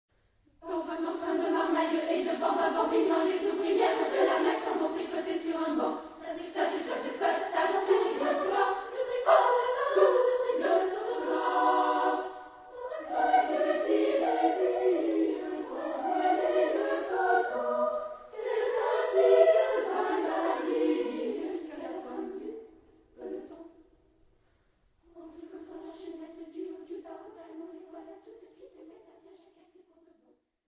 Tonart(en): G-Dur